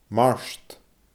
mart /maRʃd/